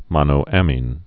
(mŏnō-ămēn, -ə-mēn)